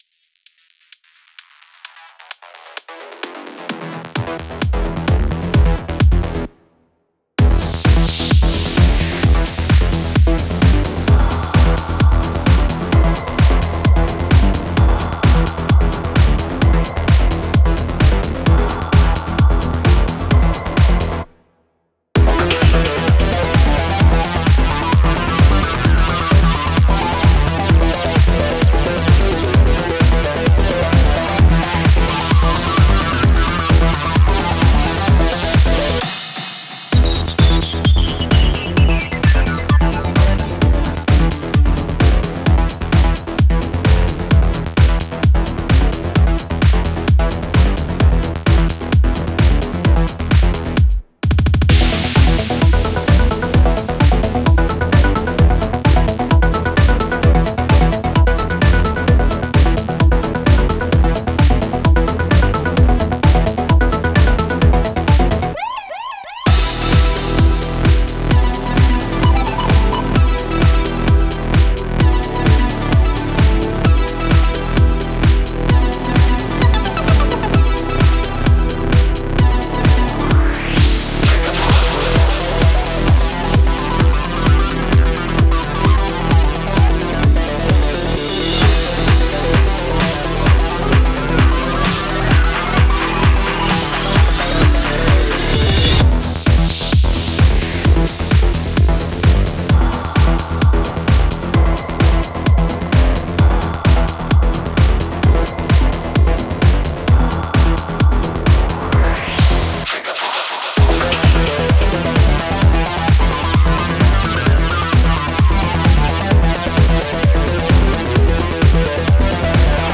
◆ジャンル：Electro House/エレクトロハウス
でも、歪み系の303っぽい音色なのでやっぱりトランスなのかもしれません。
格闘技のオープニングや選手入場にピッタリの曲調だと思います。
-10LUFSくらいでマスタリングしております。